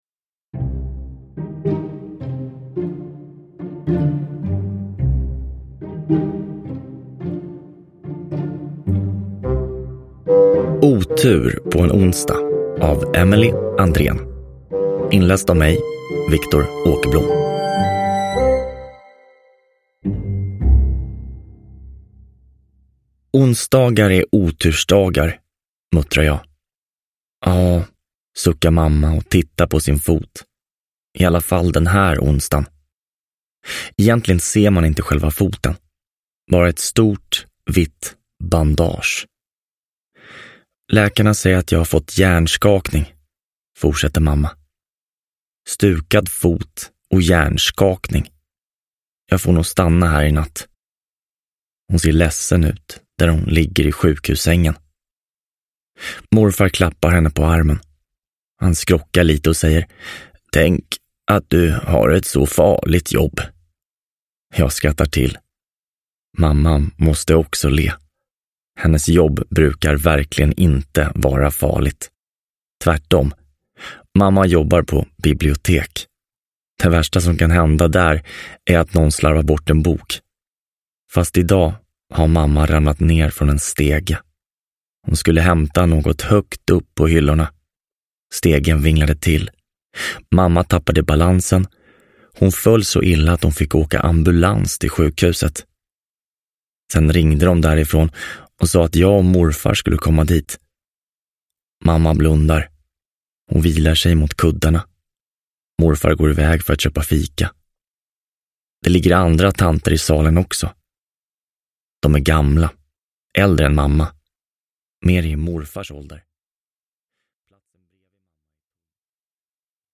Otur på en onsdag – Ljudbok – Laddas ner